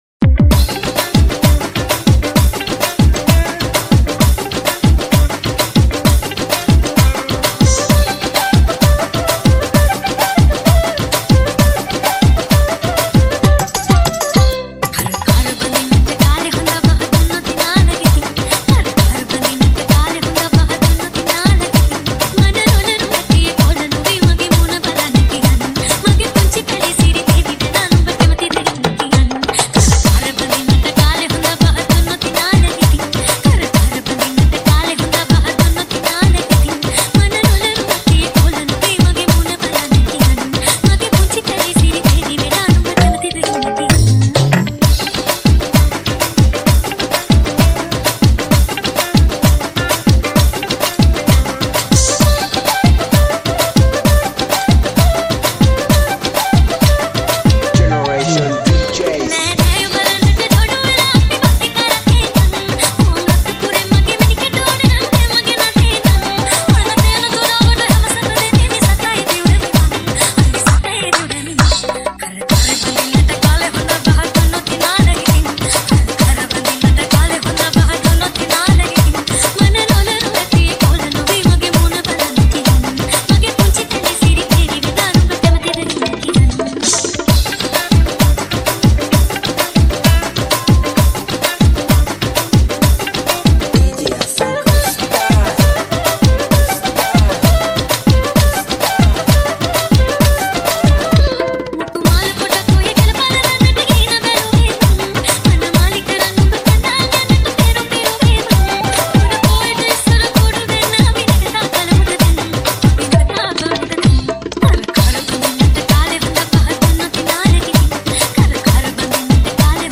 High quality Sri Lankan remix MP3 (2.5).
high quality remix